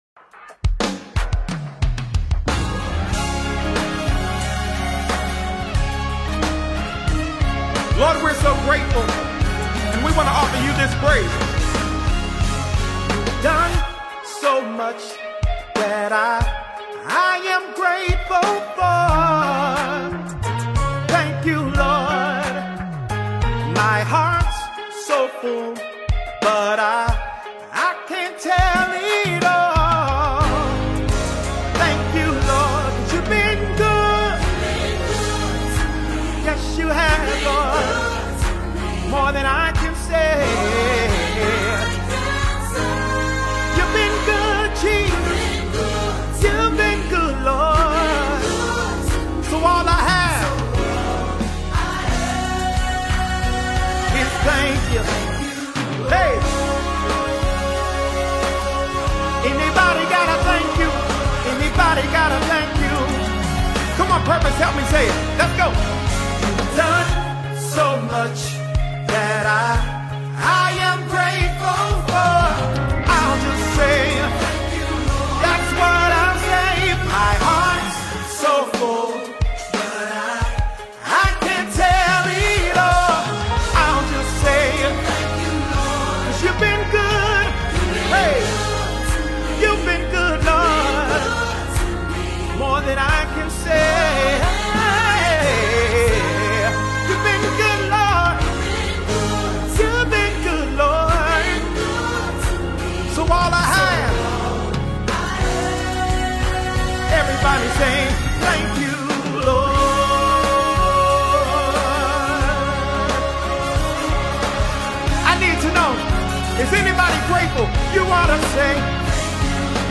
January 24, 2025 Publisher 01 Gospel 0